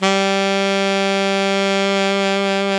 Index of /90_sSampleCDs/Roland L-CDX-03 Disk 1/SAX_Alto 414/SAX_Alto mf 414
SAX ALTOMF01.wav